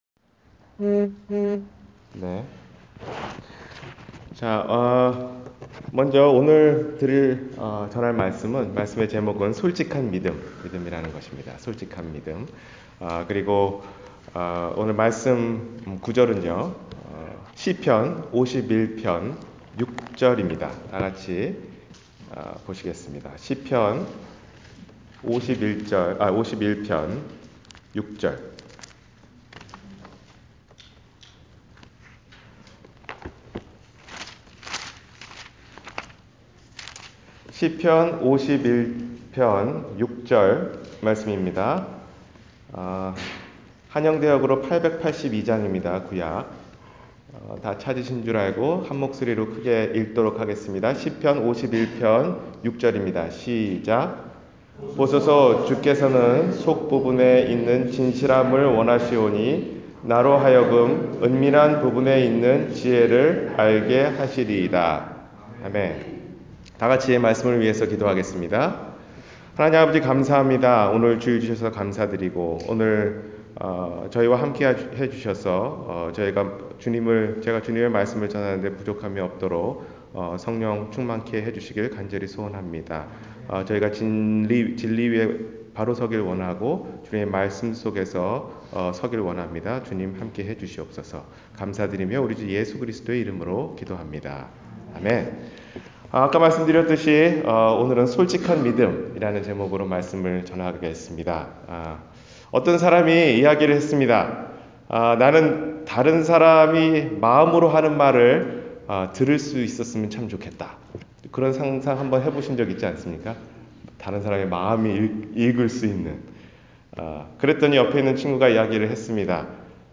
솔직한 믿음 – 주일설교